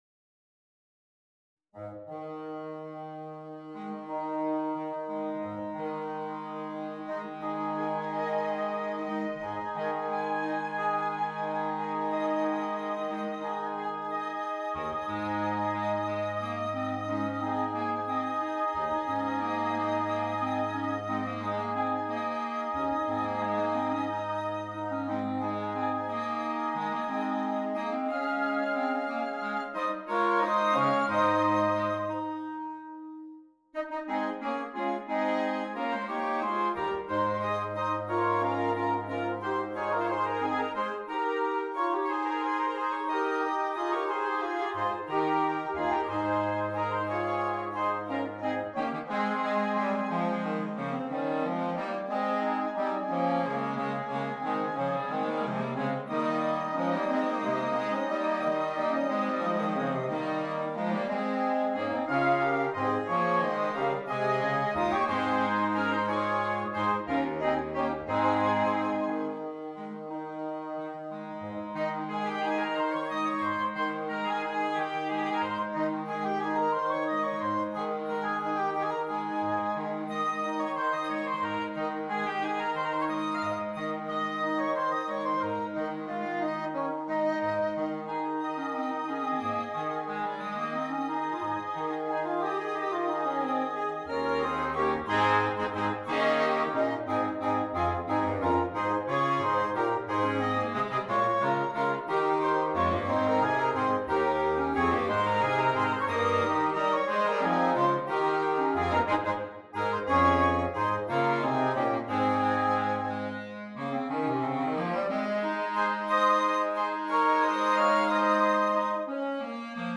Interchangeable Woodwind Ensemble
Traditional